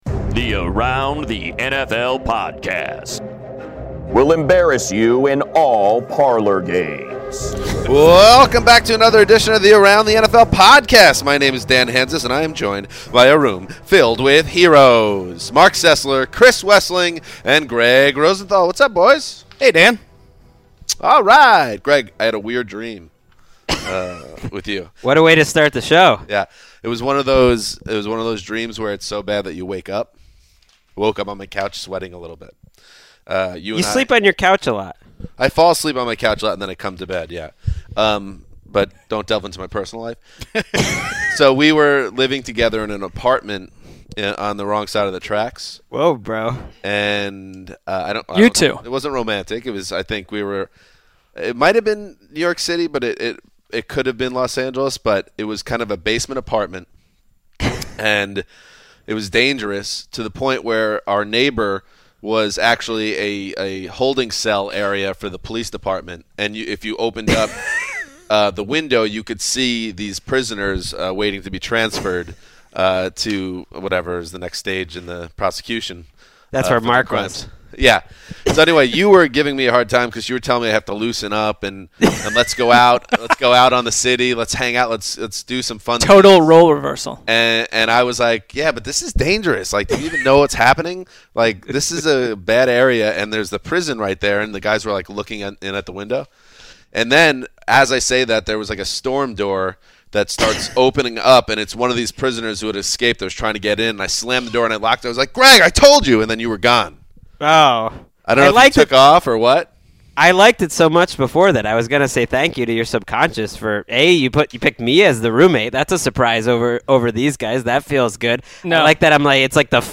Lil Debbie Live in Studio